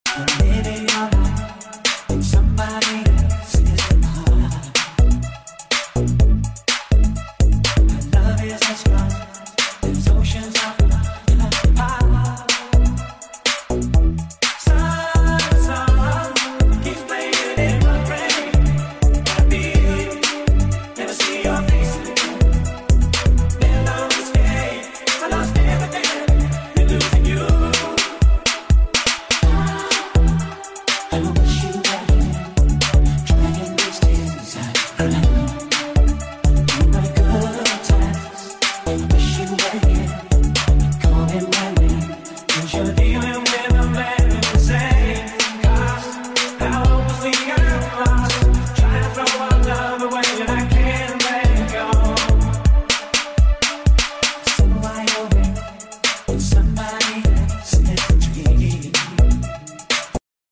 Freestyle Music